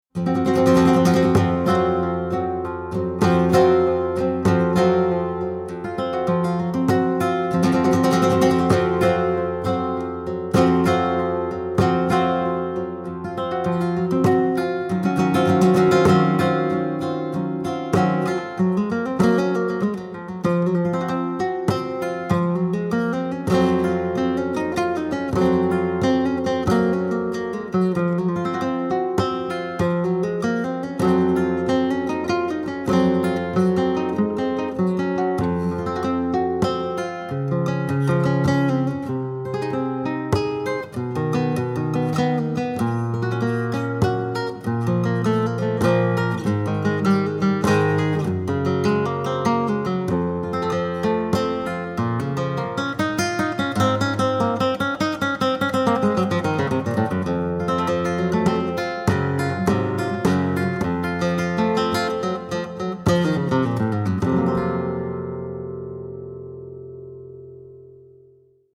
Falseta